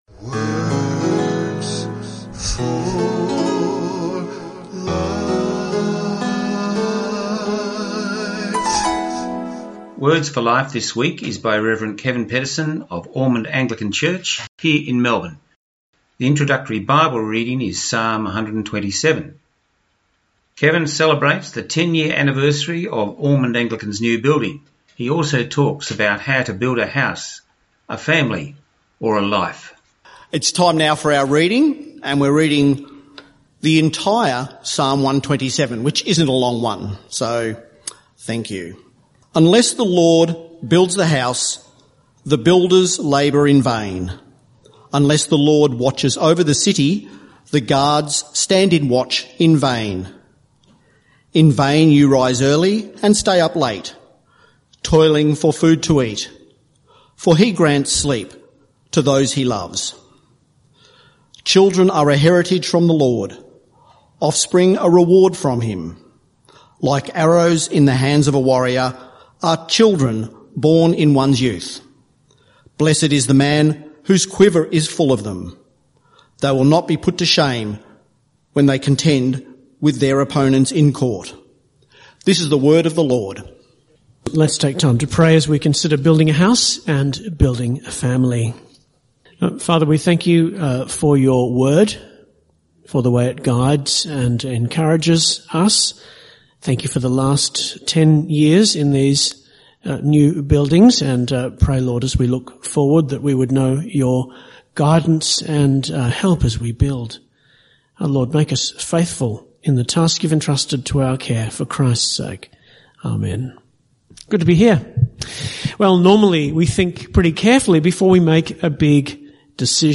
Our Christian talk on Songs of Hope